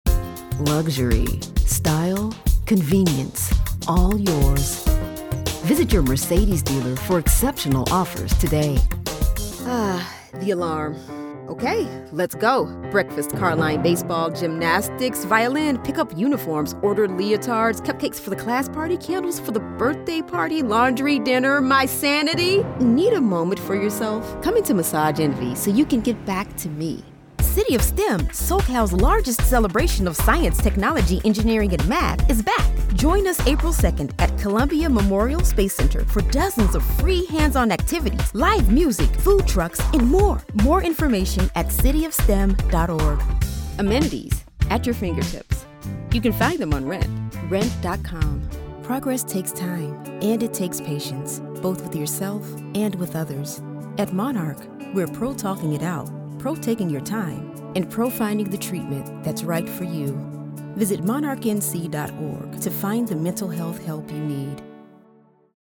Voiceover : Commercial : Women
Commercial Demo